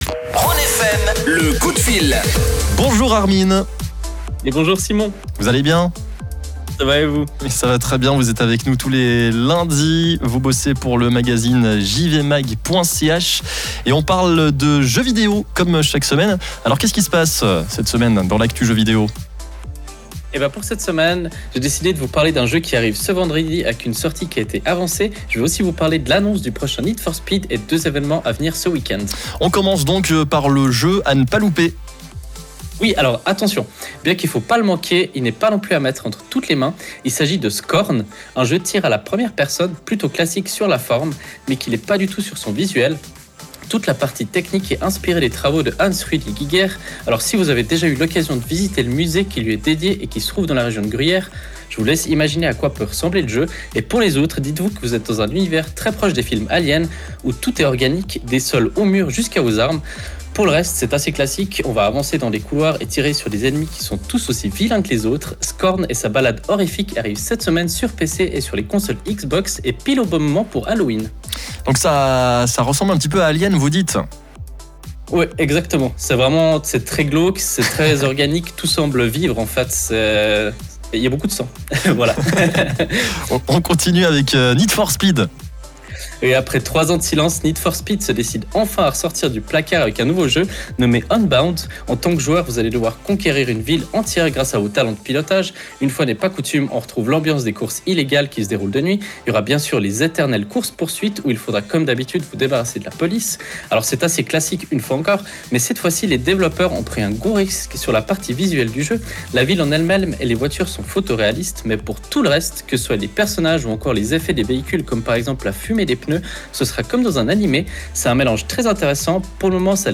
Pour réécouter le direct, ça se passe juste en dessus.